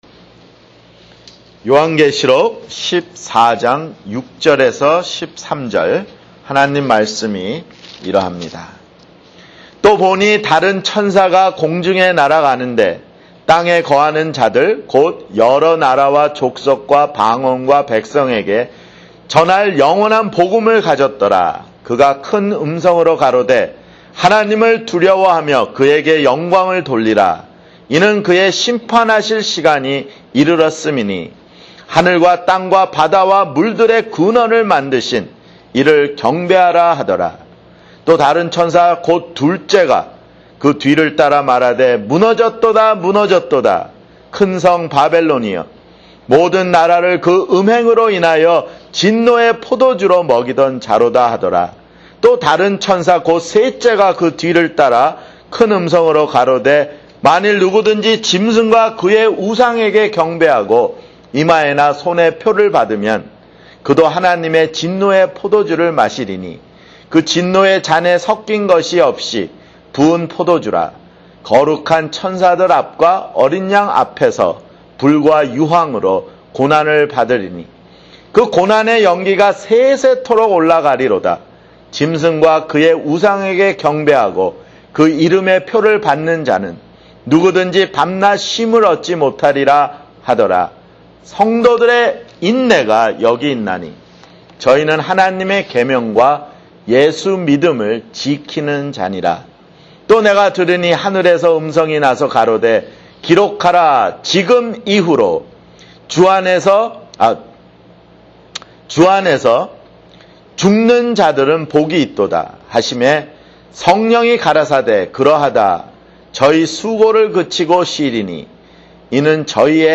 [주일설교] 요한계시록 (50)